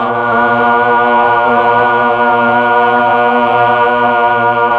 Index of /90_sSampleCDs/Keyboards of The 60's and 70's - CD1/VOX_Melotron Vox/VOX_Tron Choir